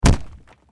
Stamp.mp3